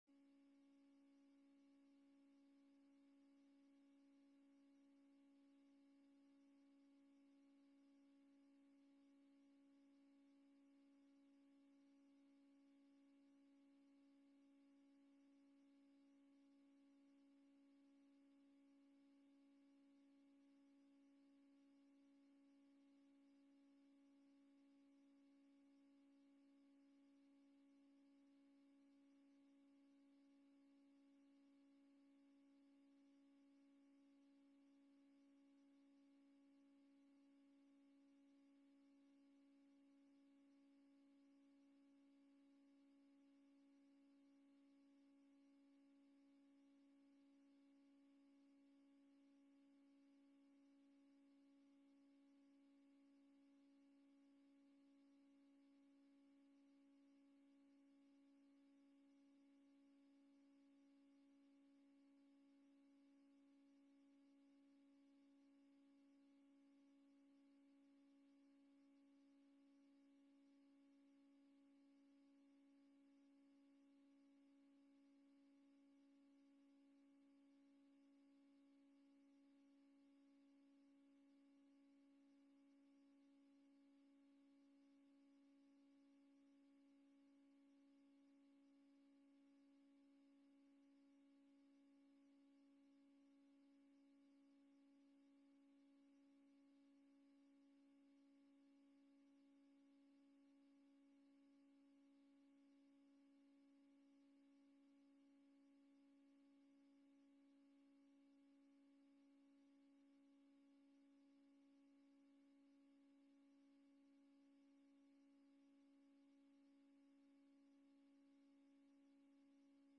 Agenda Nieuwegein - Avond van de Raad Vanuit de Raadzaal donderdag 7 januari 2021 20:00 - 22:30 - iBabs Publieksportaal
Locatie Digitale vergadering Voorzitter Henk Jan Schat